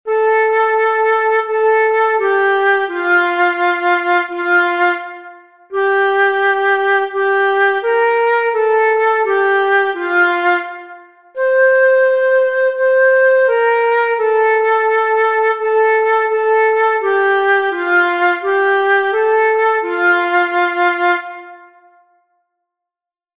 Free Sheet Music for Flute
Traditional
Its clear, repetitive phrases and accessible range make it an excellent choice for a beginner’s performance, or as a lighthearted piece in a folk music medley.
The flute’s gentle tone is perfectly suited to conveying the song’s enduring, bittersweet innocence.